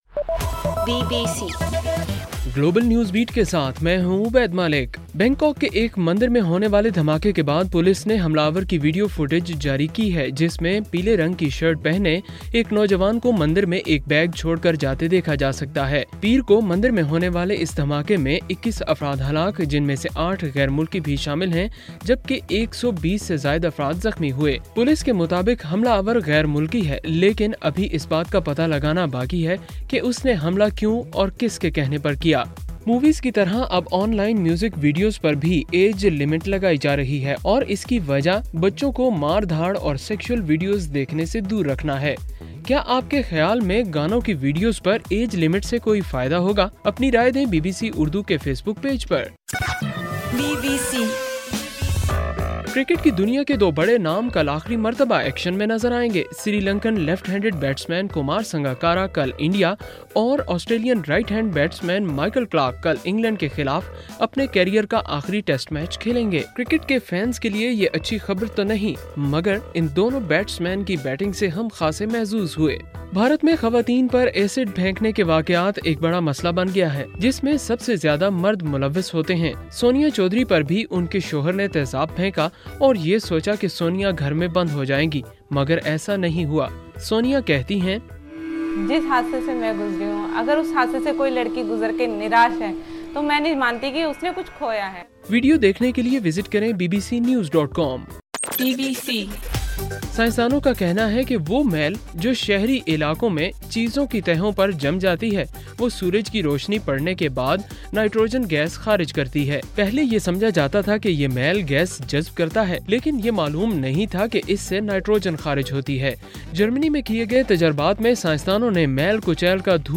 اگست 19: رات 8 بجے کا گلوبل نیوز بیٹ بُلیٹن